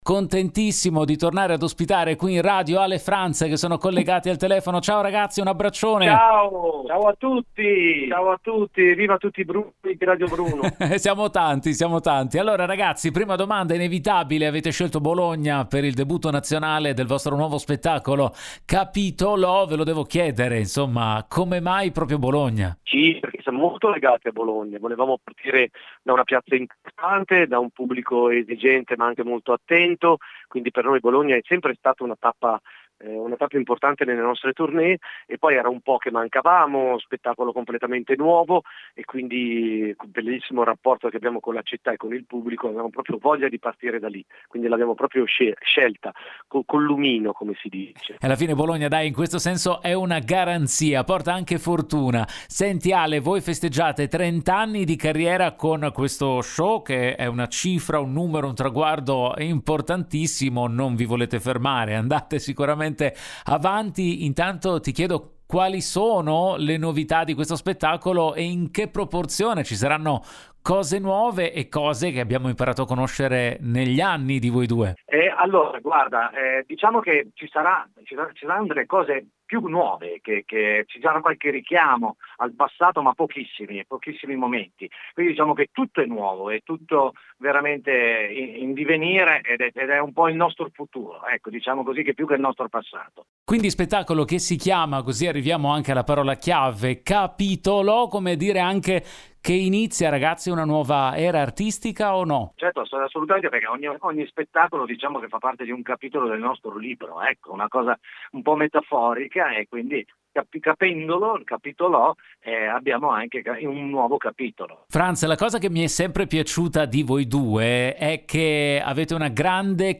Home Magazine Interviste Ale & Franz presentano “Capitol’ho”